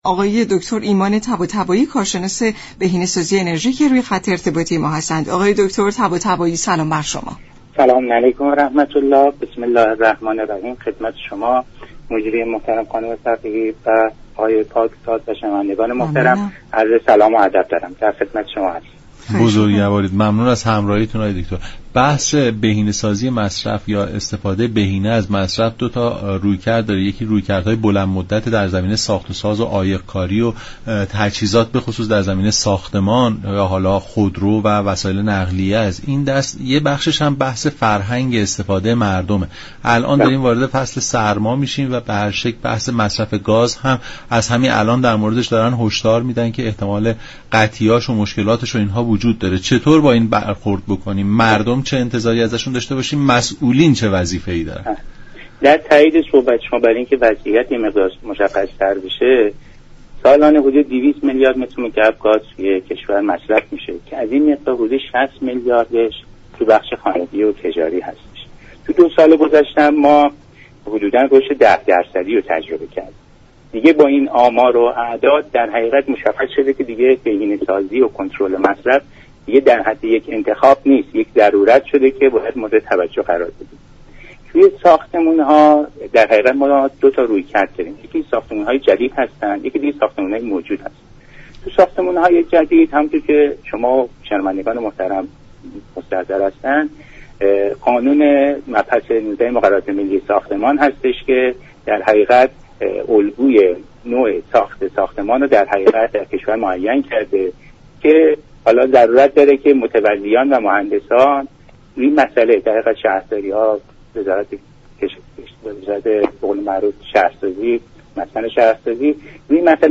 كارشناس بهینه سازی انرژی گفت: امروز بهینه سازی و كنترل مصرف یك انتخاب نیست؛ بلكه یك ضرورت است.
برنامه نمودار شنبه تا چهارشنبه هر هفته ساعت 10:20 از رادیو ایران پخش می شود.